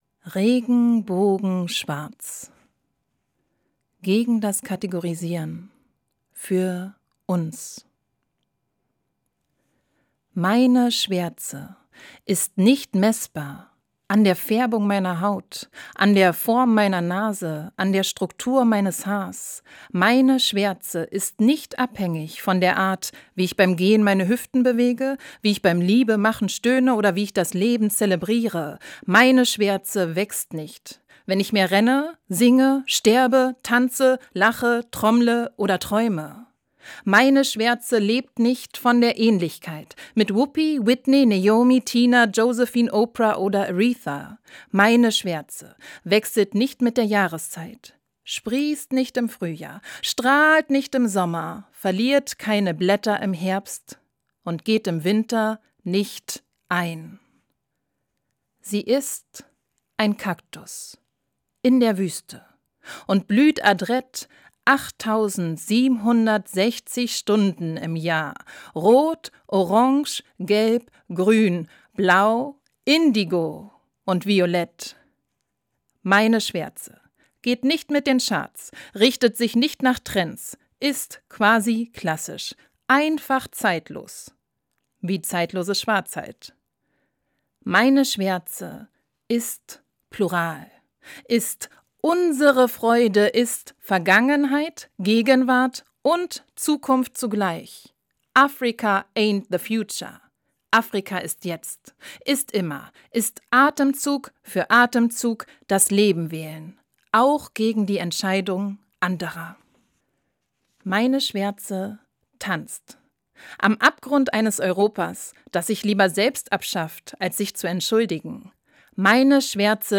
Das radio3-Gedicht der Woche: Dichter von heute lesen radiophone Lyrik.